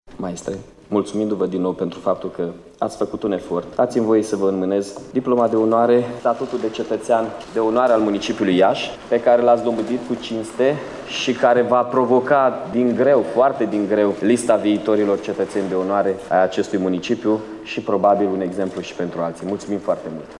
La festivitate au fost prezenţi, pe lângă reprezentanţii autorităţilor locale şi judeţene şi rectorii celor cinci universităţi de stat din Iaşi, precum şi conducerile Teatrului Naţional şi Operei Naţionale Române.
Titlul de cetăţean de onoare a fost înmânat de primarul Iaşului Mihai Chirica.